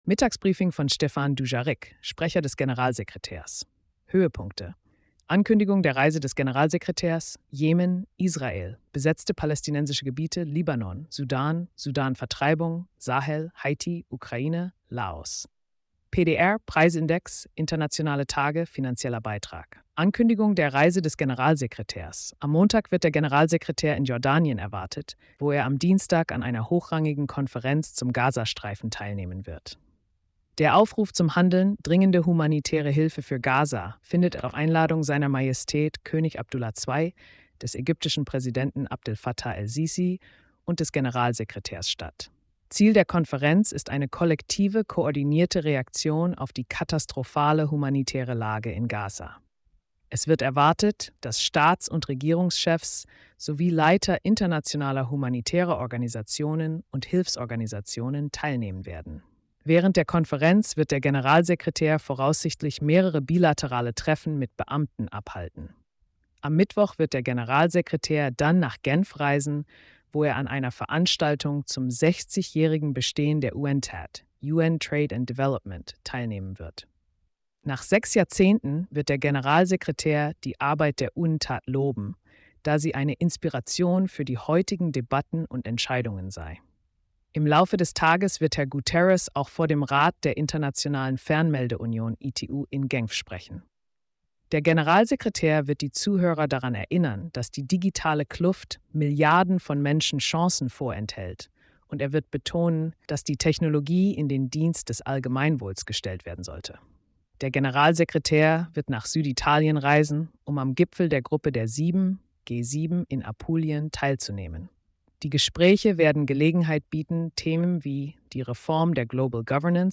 Mittagsbriefing von Stéphane Dujarric, Sprecher des Generalsekretärs.